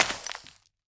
Minecraft Version Minecraft Version latest Latest Release | Latest Snapshot latest / assets / minecraft / sounds / block / suspicious_sand / break2.ogg Compare With Compare With Latest Release | Latest Snapshot